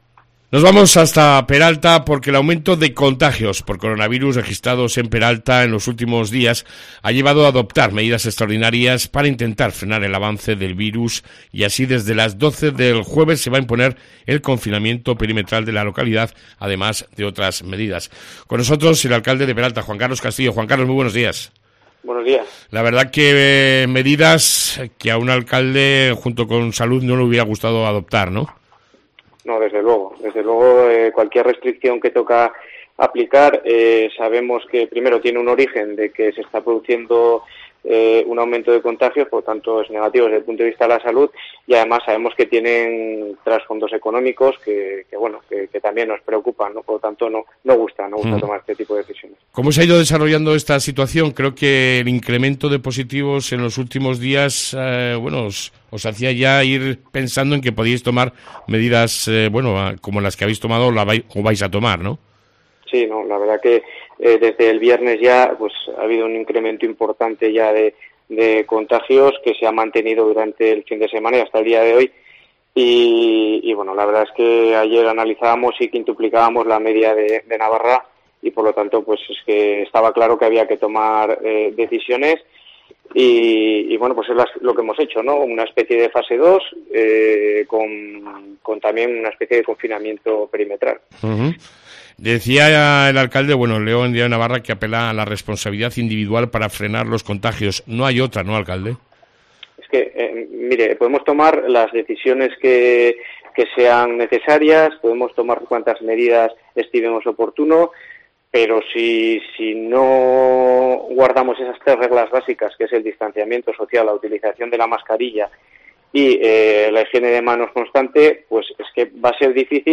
AUDIO: Entrevista con el Alcalde de Peralta Juan Carlos Castillo